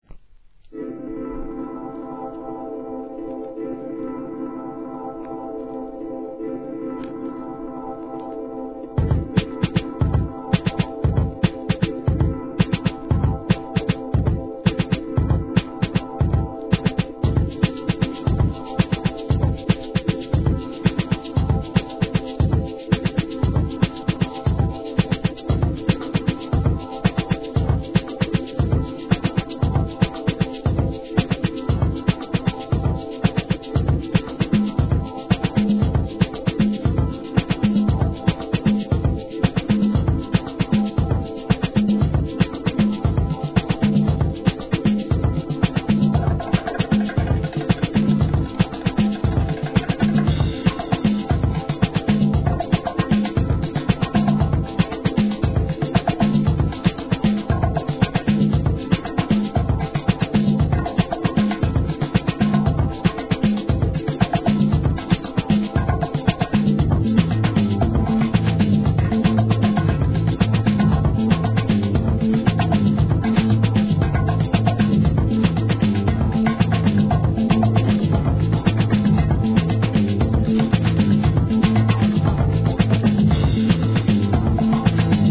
HIP HOP/R&B
ヒップホップ、ファンク、ダブなどの要素を取り入れたDOPEな世界観!!